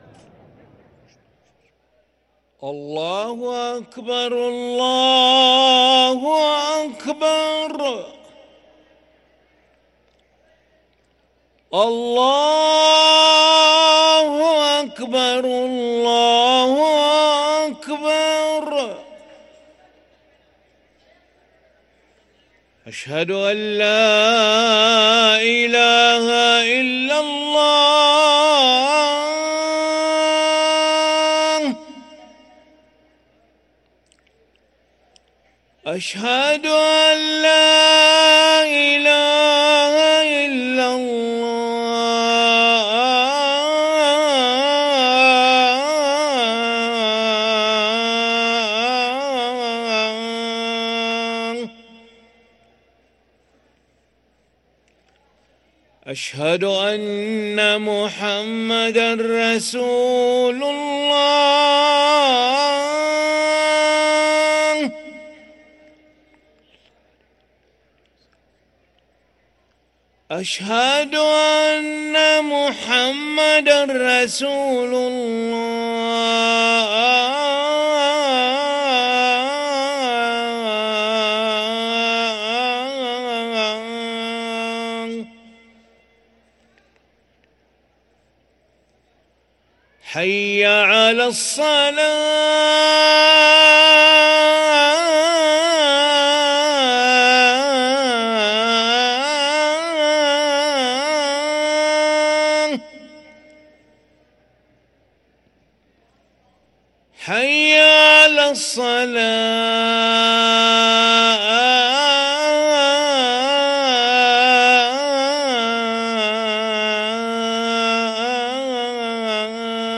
أذان العشاء للمؤذن علي ملا الخميس 3 شعبان 1444هـ > ١٤٤٤ 🕋 > ركن الأذان 🕋 > المزيد - تلاوات الحرمين